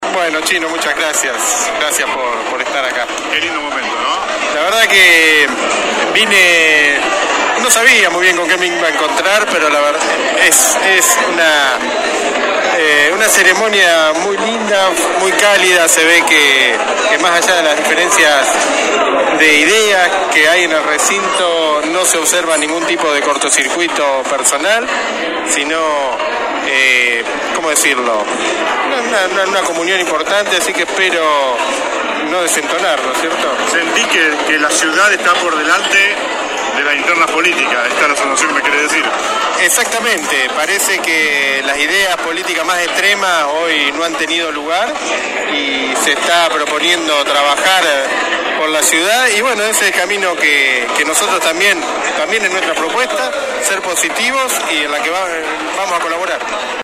(incluye audios) Los flamantes ediles dejaron este viernes en la 91.5 su reflexión por lo vivido en el marco de la sesión preparatoria que tuvo lugar en la tarde del jueves en el salón «Dr. Oscar Alende» del HCD.